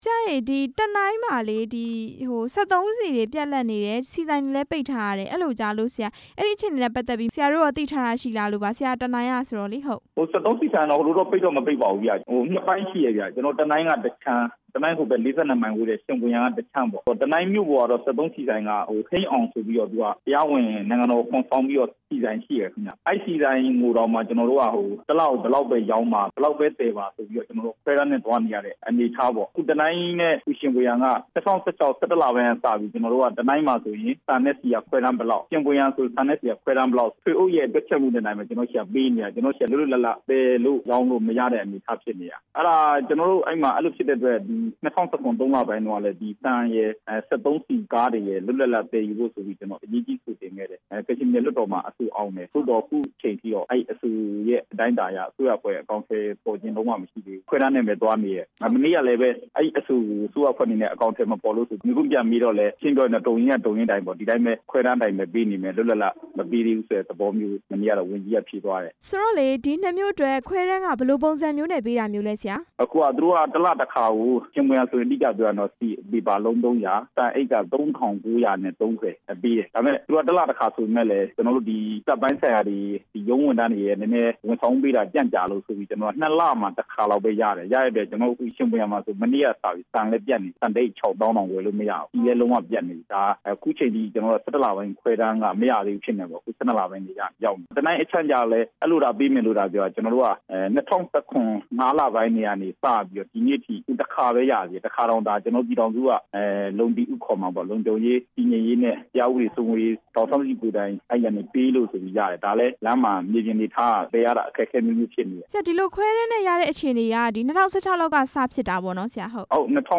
တနိုင်းမြို့နယ်မှာ ဆန်ပြတ်လတ်မှု အခြေအနေ မေးမြန်းချက်